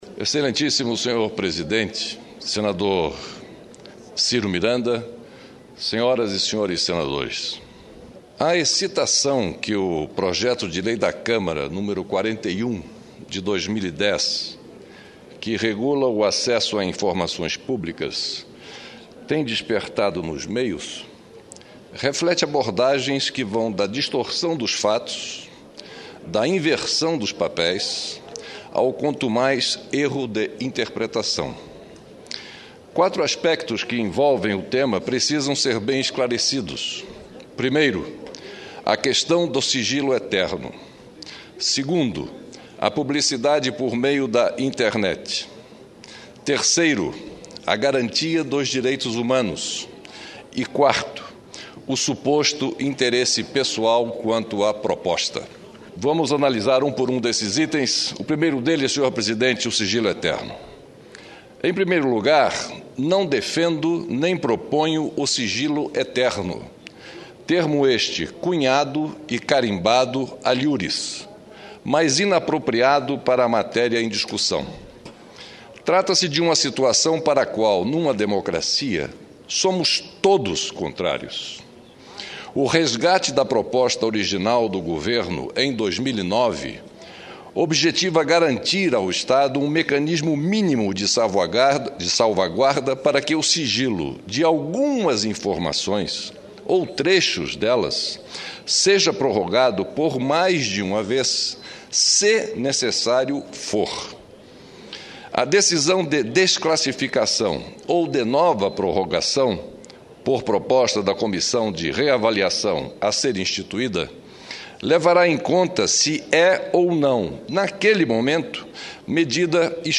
O senador Fernando Collor continua a esclarecer alguns dos pontos do Projeto de Lei da Câmara nº 41, de 2010, que regula o acesso a informações públicas.